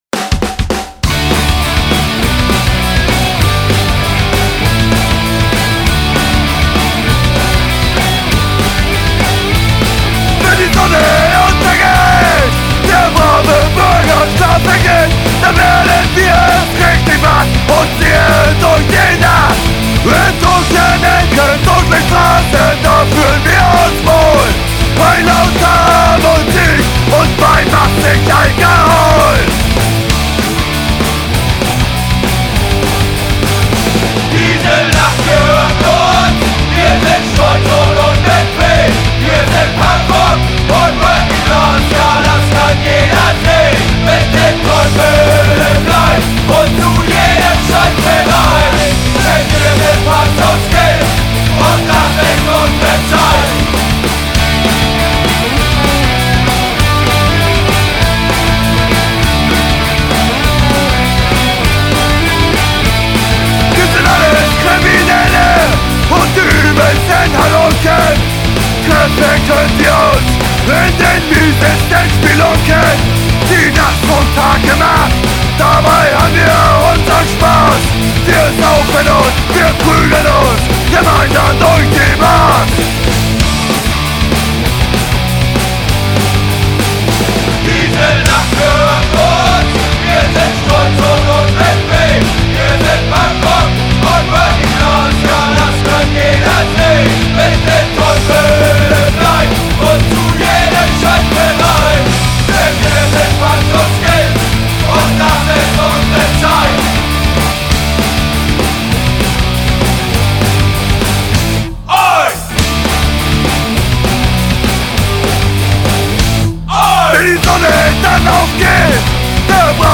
Laut – ehrlich – authentisch, mit dem Charme der Strasse!